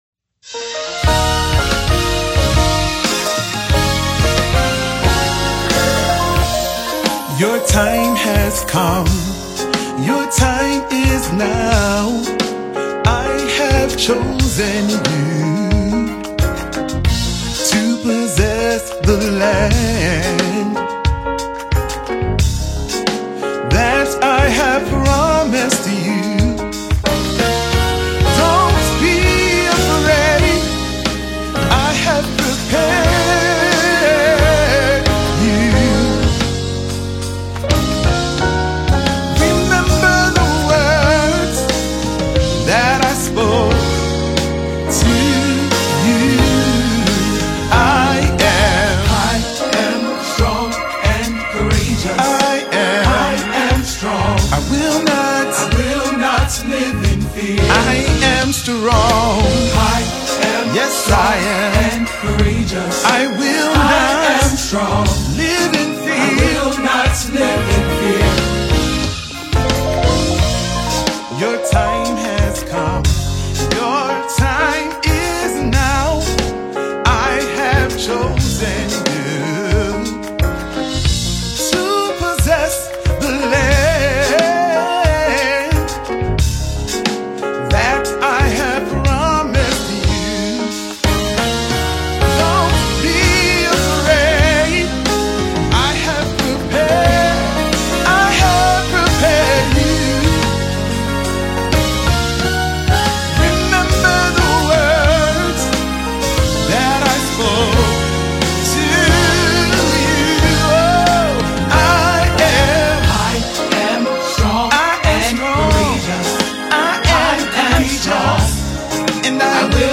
gospel
a stirring anthem of faith and perseverance
soulful and emotive delivery
uplifting chorus and heartfelt verses